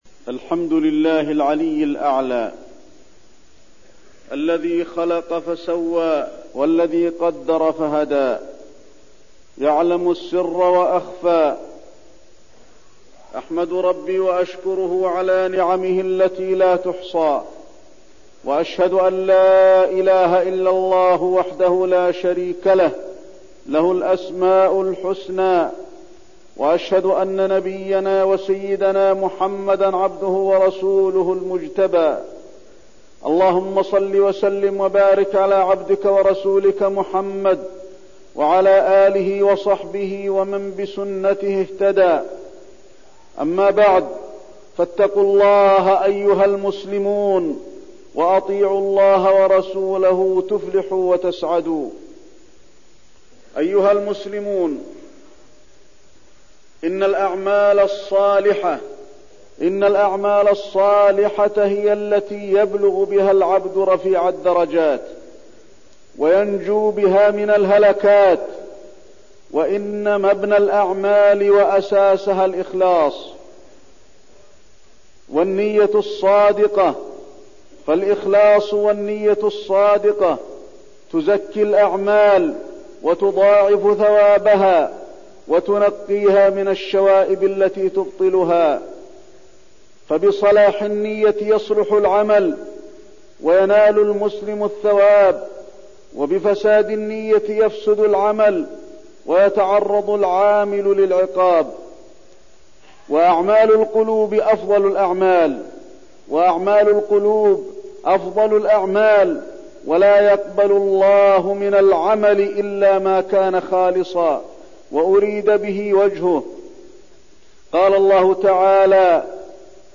تاريخ النشر ٢٨ جمادى الآخرة ١٤١٢ هـ المكان: المسجد النبوي الشيخ: فضيلة الشيخ د. علي بن عبدالرحمن الحذيفي فضيلة الشيخ د. علي بن عبدالرحمن الحذيفي الإخلاص